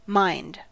Ääntäminen
IPA : /maɪnd/ US : IPA : [maɪnd]